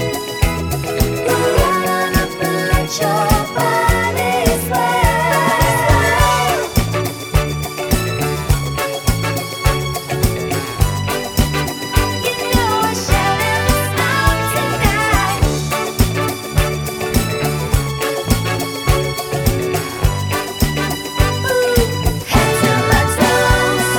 One Semitone Down Pop (1980s) 3:13 Buy £1.50